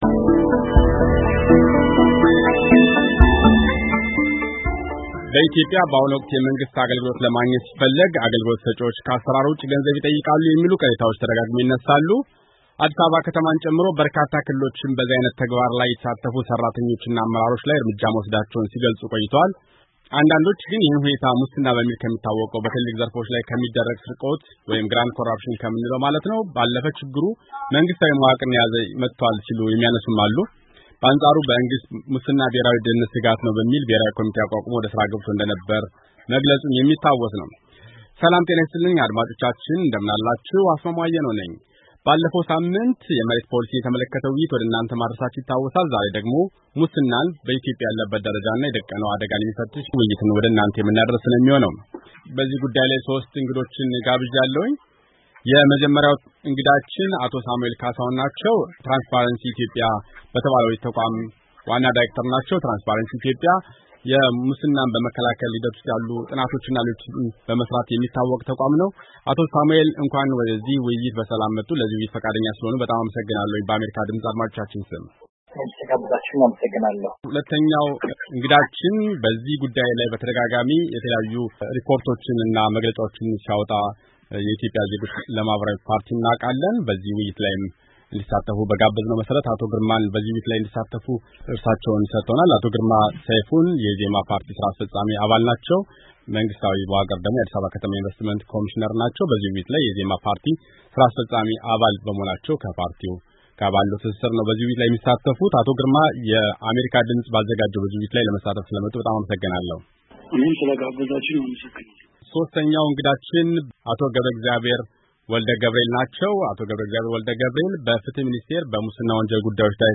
ኢትዮጵያ ለሙስና ወንጀሎች ያላት ተጋላጭነት በየጊዜው እየጨመረ መምጣቱን፣ “ትራንስፓረንሲ ኢንተረናሽናል” የተባለው ሉላዊ ተቋም ይገልጻል፡፡ የአሜሪካ ድምፅም ቀደም ሲል በጉዳዩ ላይ ጋብዞ ያወያያቸው እንግዶችም፣ በመንግሥታዊ የአገልግሎት መስጫዎችና በሌሎችም ዘርፎች ሙስና እየተባባሰ መኾኑን አመልክተዋል፡፡ አሁንም በኢትዮጵያ ሙስና ያለበትን ኹኔታ በተመለከተ፣ የአሜሪካ ድምፅ እንግዶችን ጋብዞ አወያይቷል፡፡ ተወያዮቹ የ“ትራንስፓረንሲ ኢትዮጵያ” ዋና...